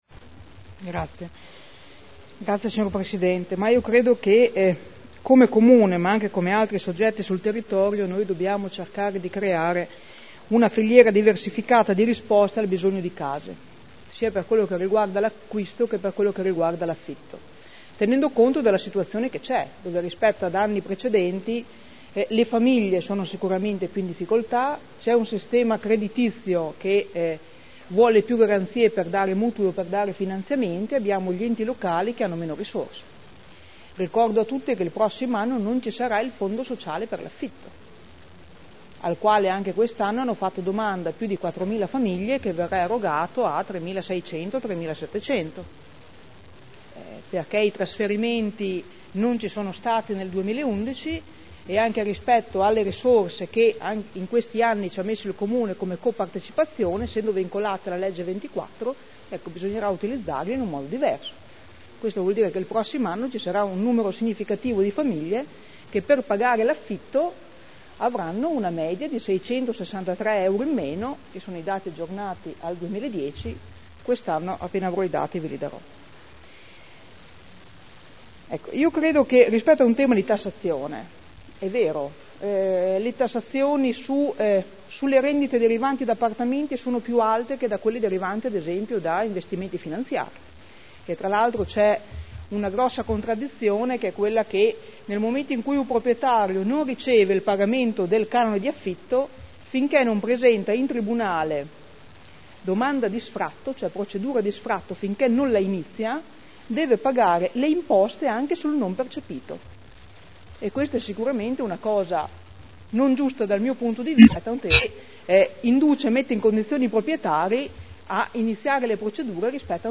Seduta del 27/06/2011. Interrogazione delle consigliere Morini e Urbelli (P.D.) avente per oggetto: “Effetti della cd. “cedolare secca” sul mercato degli affitti” Dibattito